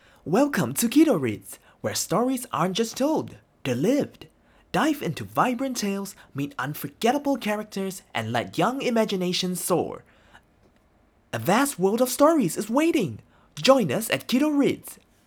Male
Energetic Young Low Conversational
KiddoReads Active, happy, promotion!